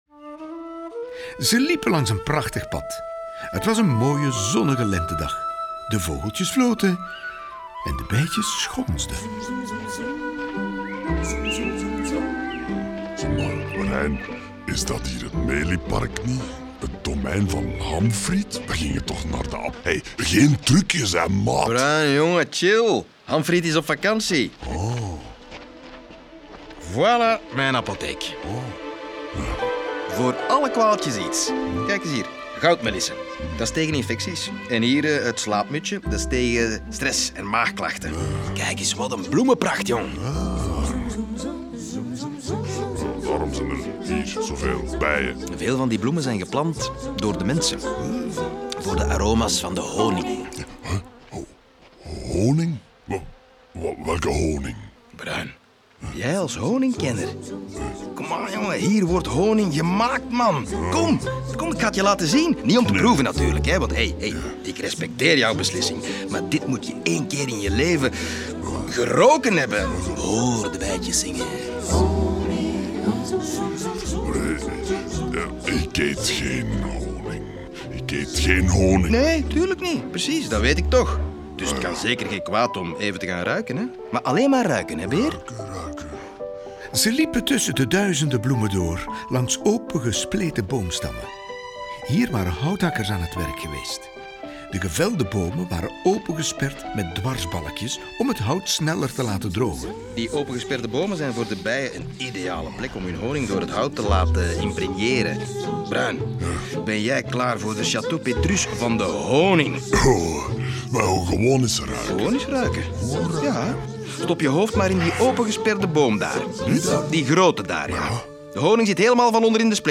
Ze worden verteld, gespeeld en gezongen door Vlaanderens meest getalenteerde acteurs en zitten boordevol humor, liedjes en knotsgekke geluidseffecten.